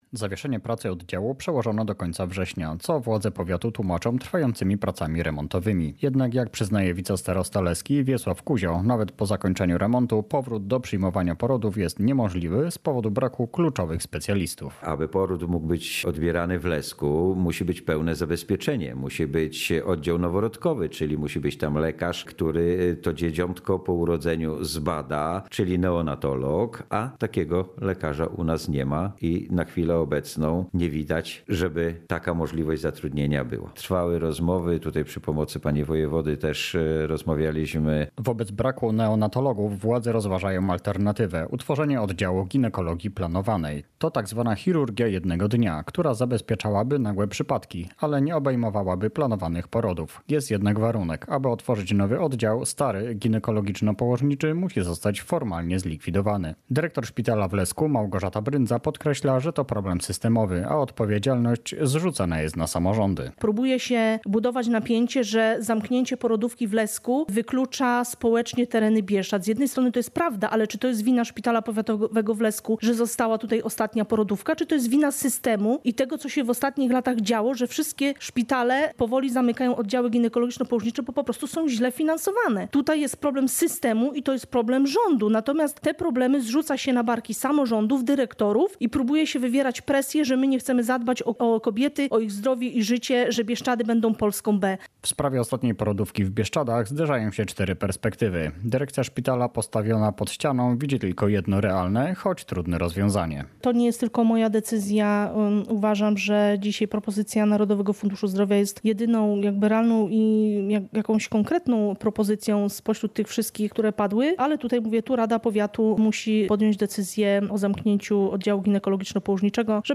Wicestarosta leski, Wiesław Kuzio, wyjaśnia, że sytuacja została przedstawiona radnym po spotkaniu w Narodowym Funduszu Zdrowia.